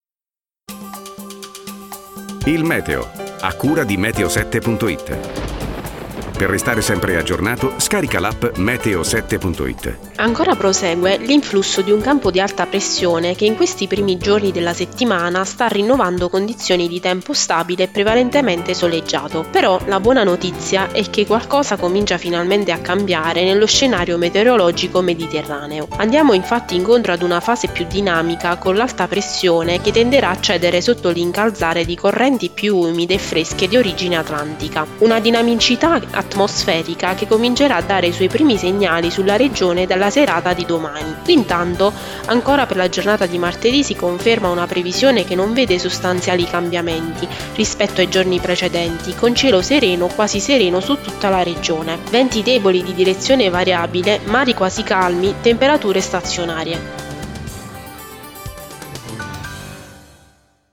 Previsioni audio per Basilicata e Campania 29/10/2019 - Meteo7